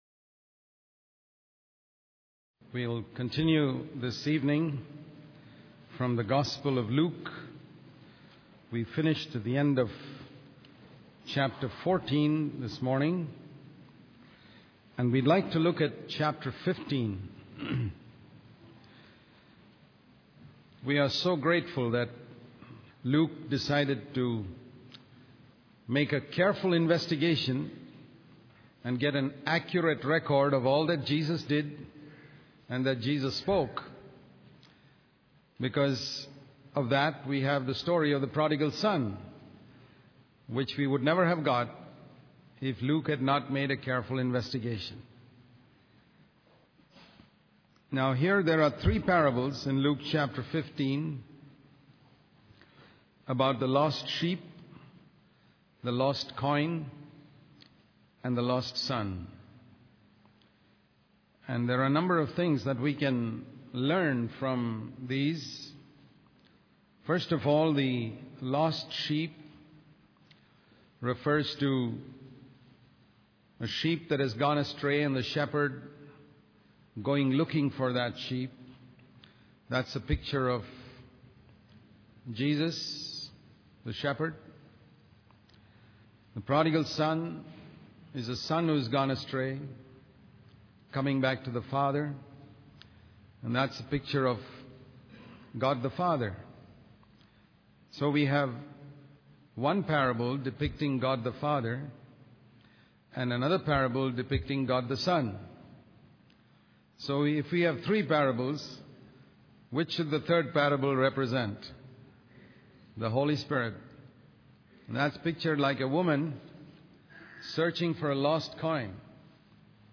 In this sermon, the preacher emphasizes the importance of having a passion for lost souls and a desire to see them transformed.